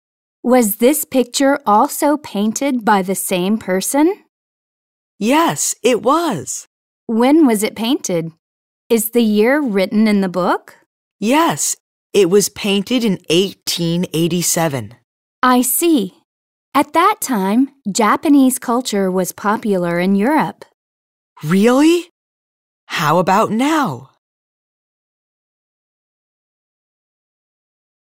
2020年5月13日(水) 英語科　教科書予習用　リスニング教材について